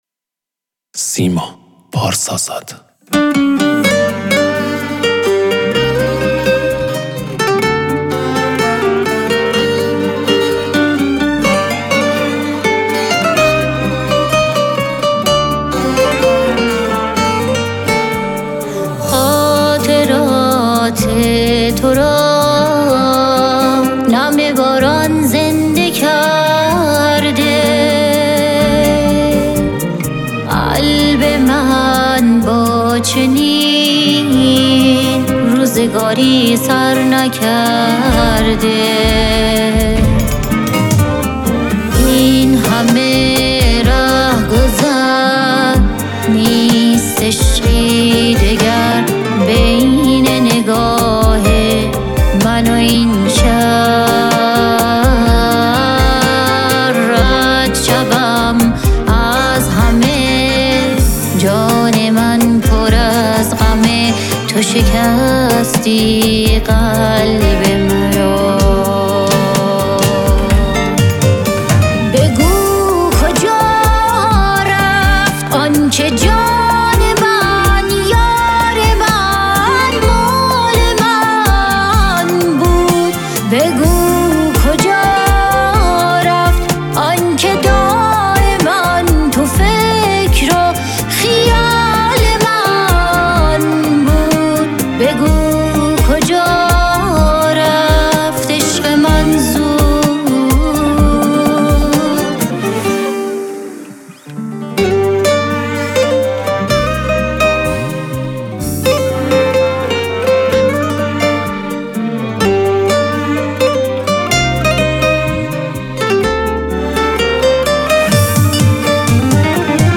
ترانه سرا و خواننده ایرانی
پاپ